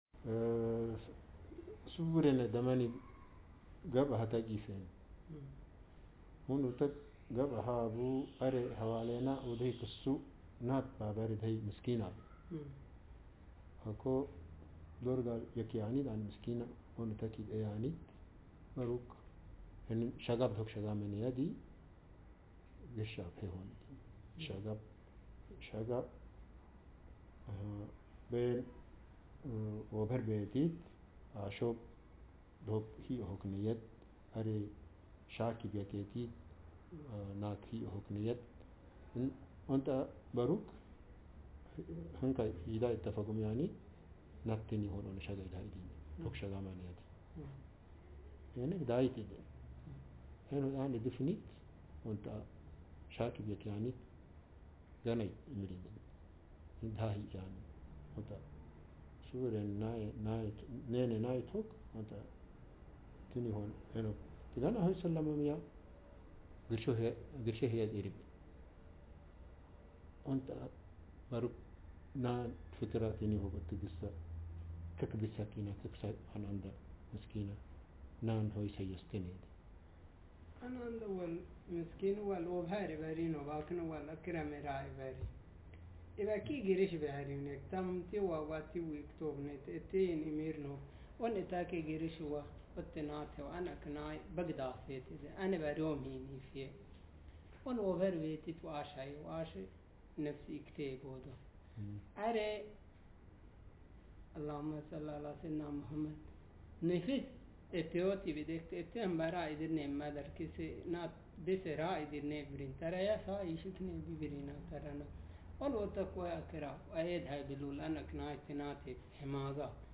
Speaker sexm/m
Text genreconversation